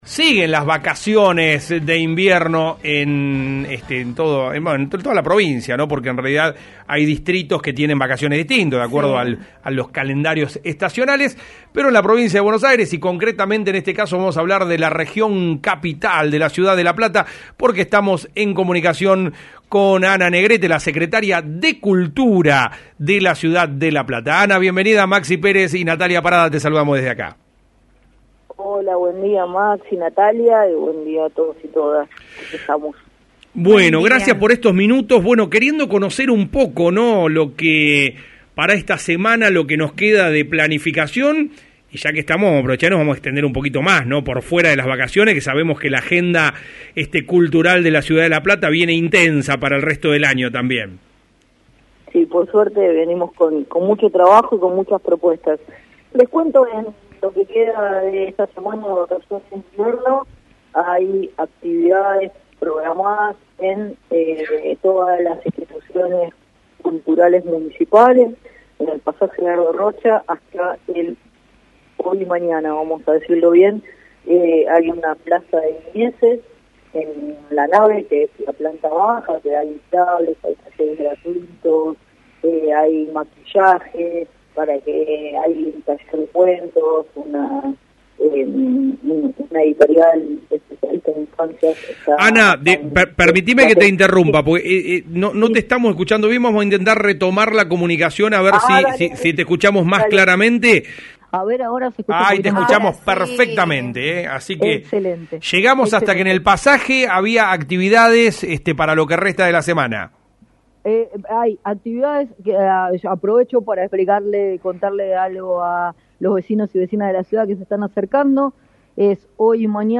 La Secretaria de Cultura de la ciudad de La Plata, Ana Amelia Negrete, detalló la variada agenda cultural preparada para las vacaciones de invierno y los desafíos para la segunda parte del año en una entrevista reciente.